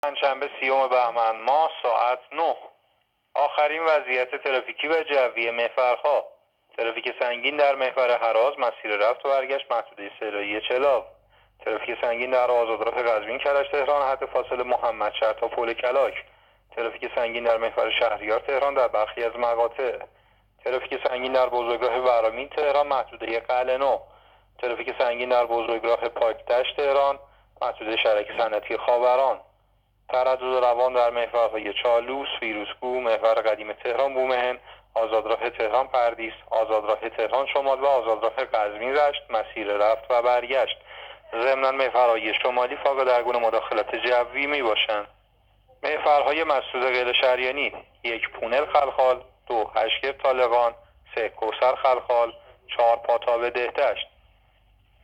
گزارش رادیو اینترنتی از آخرین وضعیت ترافیکی جاده‌ها ساعت ۹ سی‌ام بهمن؛